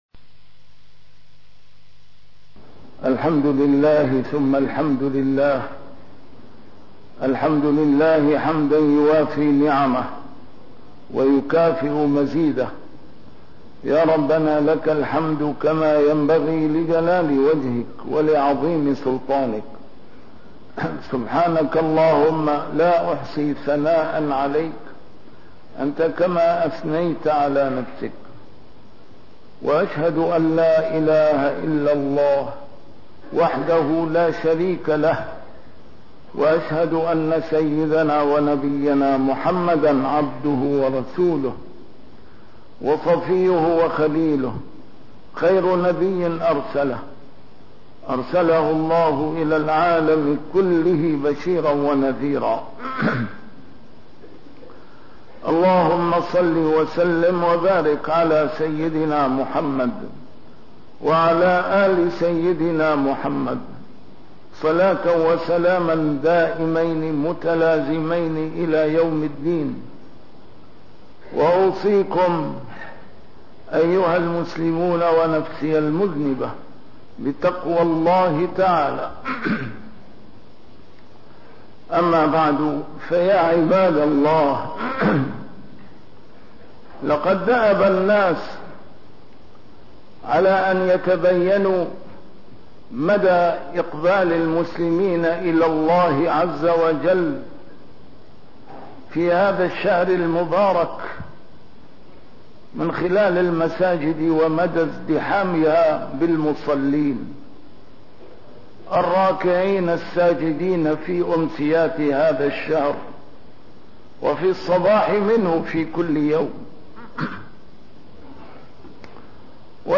نسيم الشام › A MARTYR SCHOLAR: IMAM MUHAMMAD SAEED RAMADAN AL-BOUTI - الخطب - مقياس الإقبال على الله هو العمل الصالح وترك الفساد